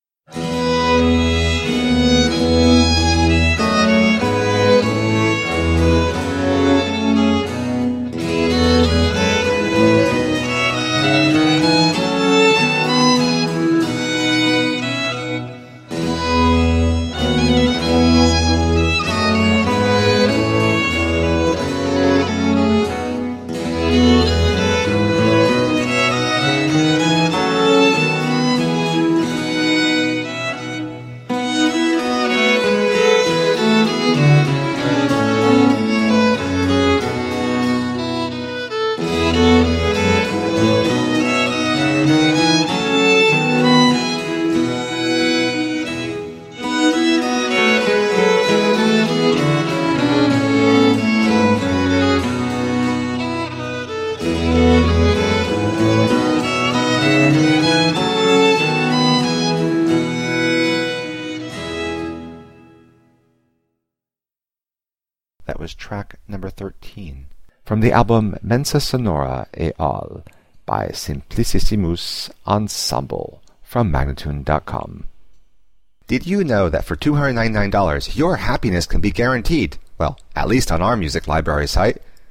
17th and 18th century classical music on period instruments
Classical, Instrumental Classical, Classical Period, Baroque